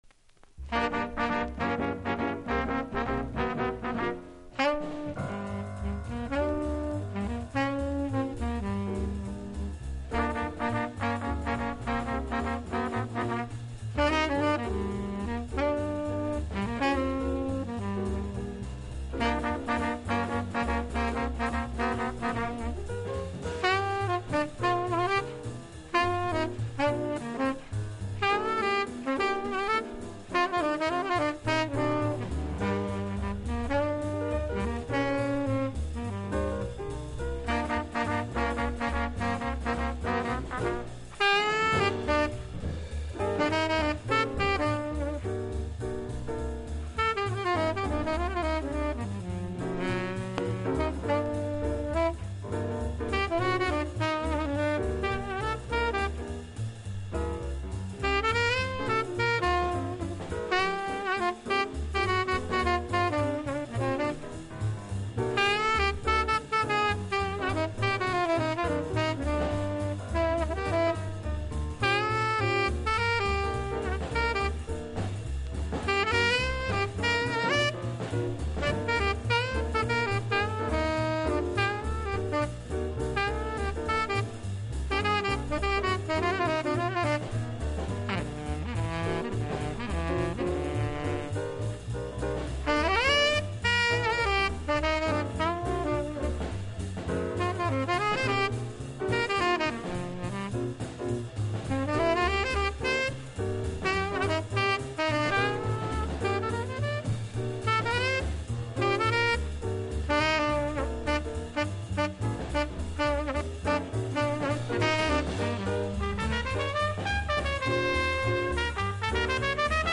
（プレス・小傷によりチリ、プチ音ある曲あり）
Genre US JAZZ